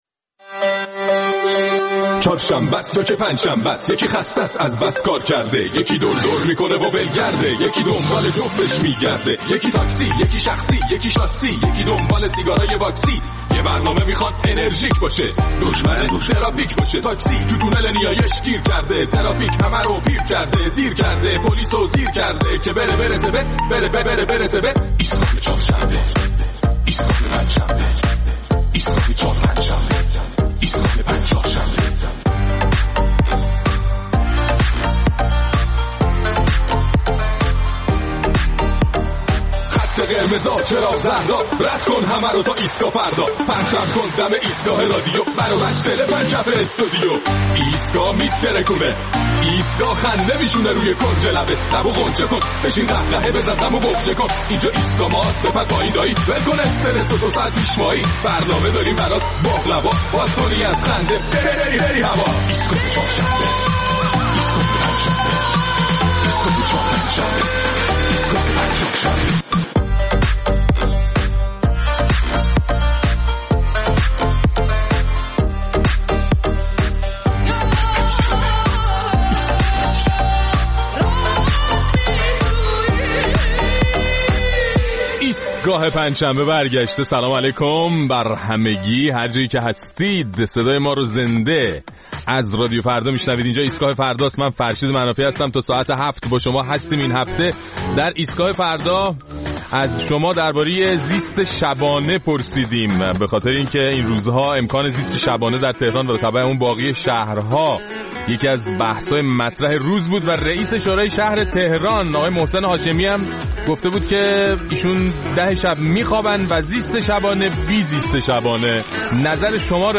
در این برنامه ادامه پاسخ‌های شنوندگان برنامه را در مورد زیست شبانه و موضع‌گیری‌های بعضی از مسئولین در این مورد می‌شنویم.